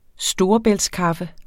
Udtale [ ˈsdoːʌbεlds- ]